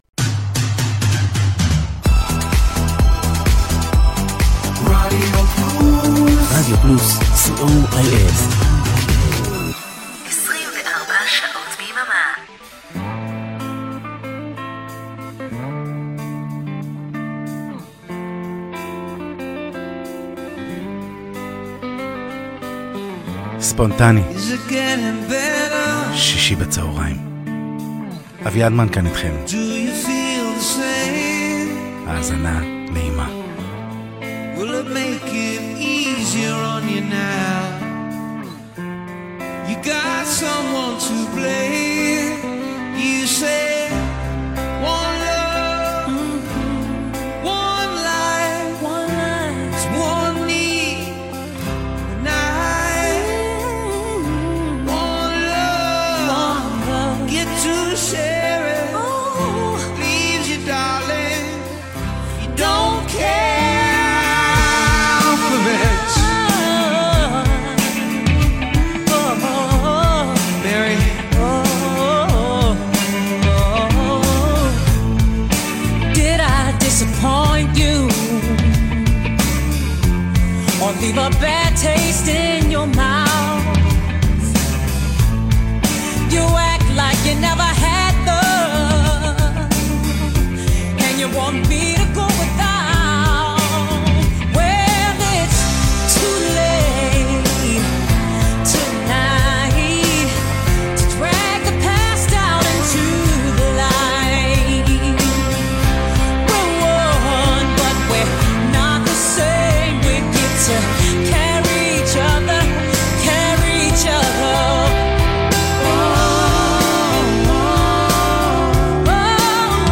בלי נושא מרכזי, בלי אג’נדה, בלי הרבה מלל; פשוט שירים יפים ורגועים לסיום של שבוע עמוס, פיזית ורגשית.